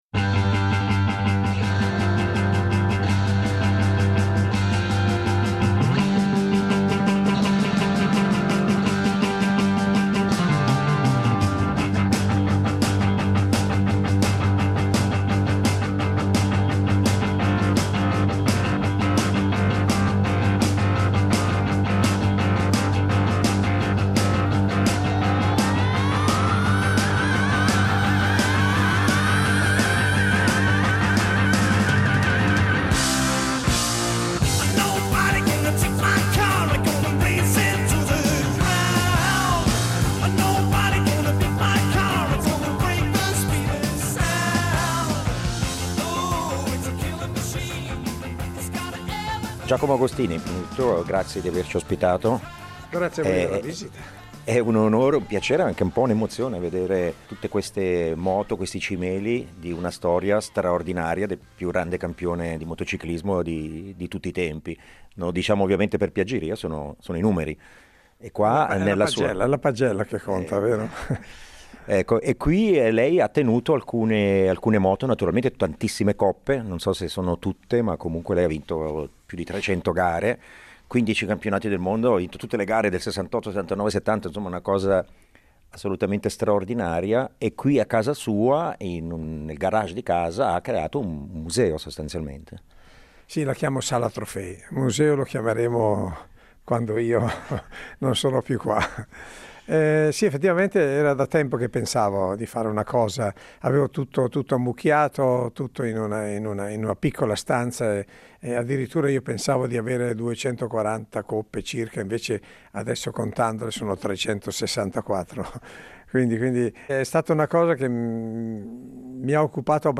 Giacomo Agostini si racconta e ricorda il suo percorso da quando bimbo di 9 anni “rubò” la moto al papà per farsi un giro in paese fino ai nostri giorni, pensionato dalle gare ma sempre vicino al suo sport che segue come commentatore. L’uomo di tutti i record ci accompagna tra ricordi, aneddoti, e un po’ di nostalgia per gli anni in cui era lui il Re dei circuiti.